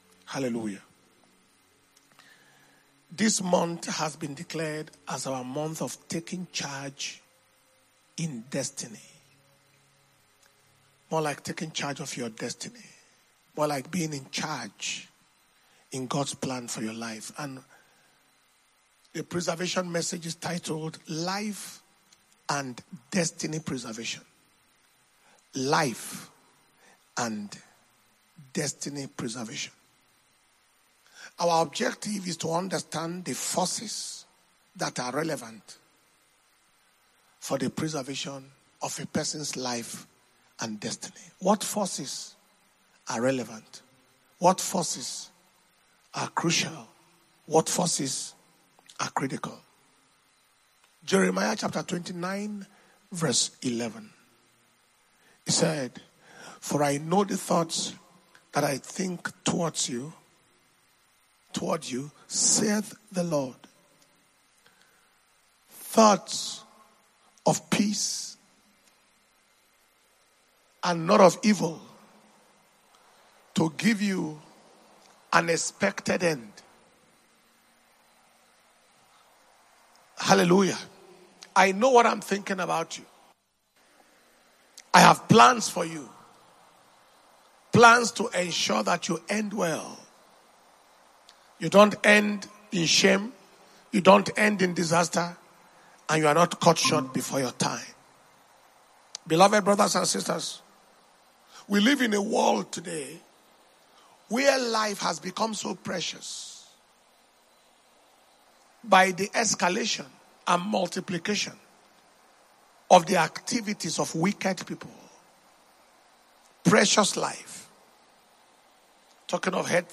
May 2025 Preservation And Power Communion Service - Wednesday 7th May, 2025